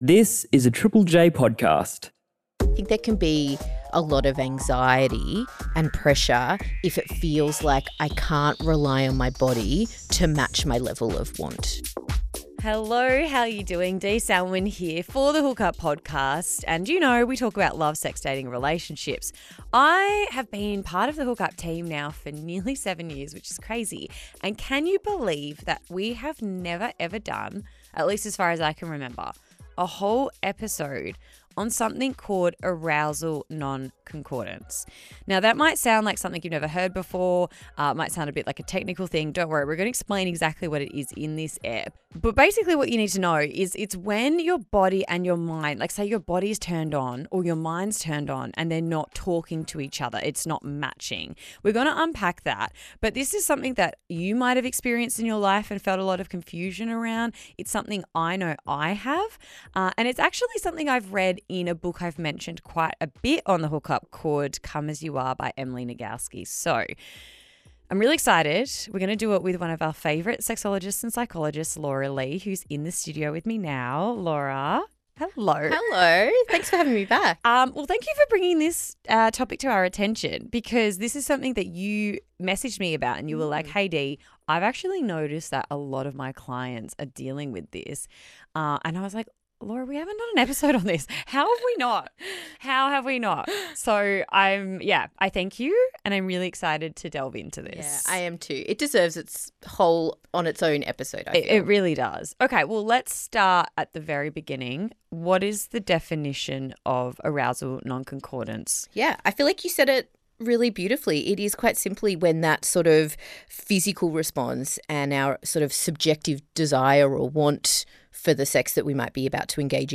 It is recorded on the lands of the Wurundjeri people of the Kulin nation.